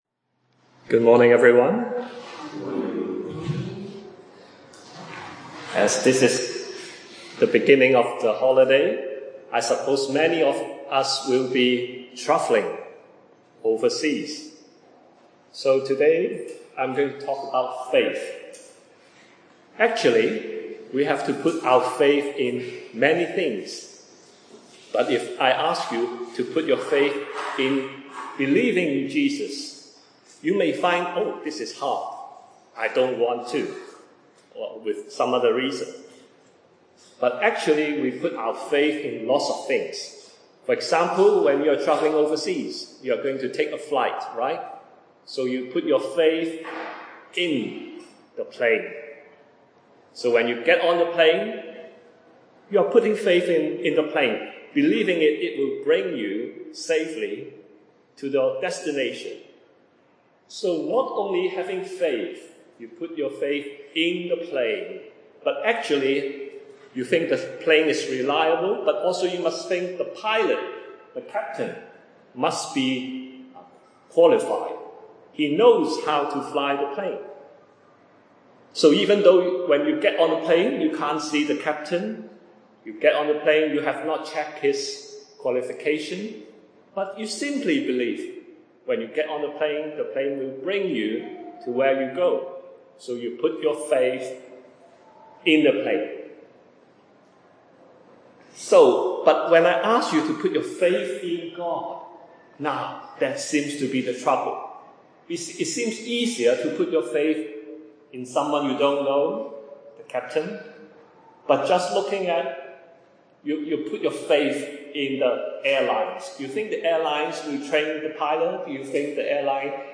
Scripture reference: John 20:30-31, Romans 1:18-21 A Gospel message regarding why we can put our faith in Jesus Christ as our Saviour.